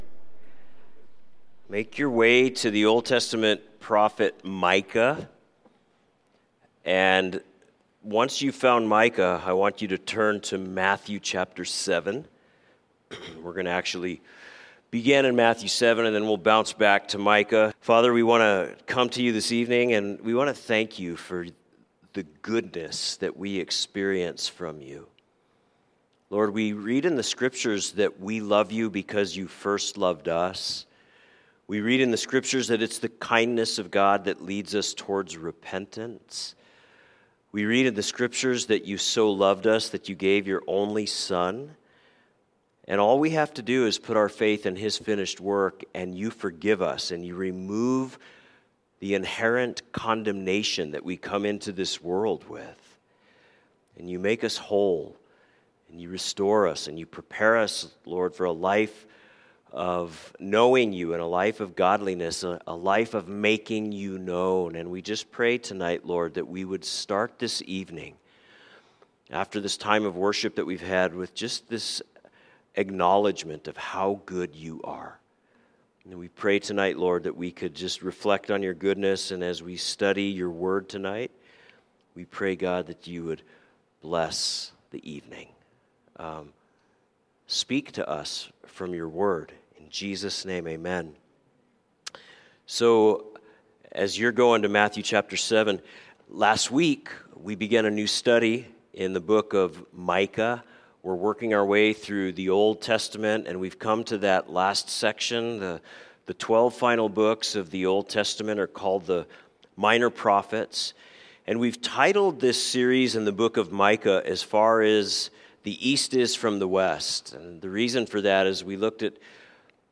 A message from the series "Wednesday Evening."